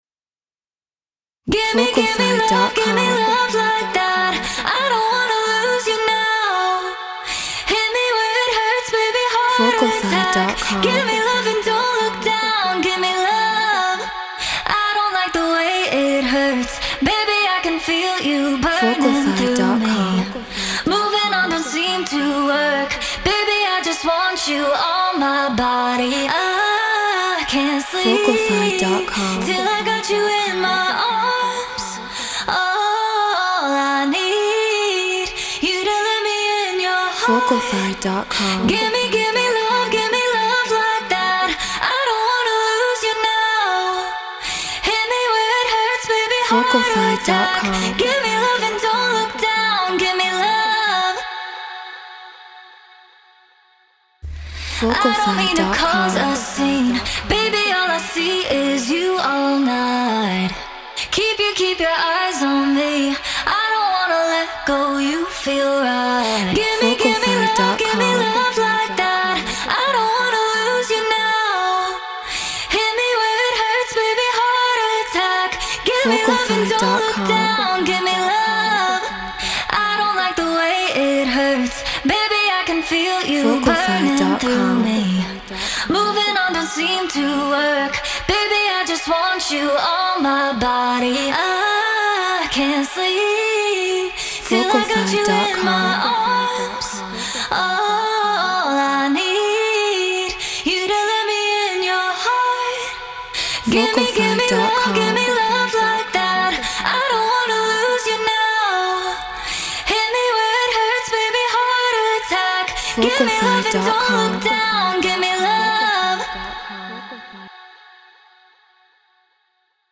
Hypertechno 155 BPM F#min
Treated Room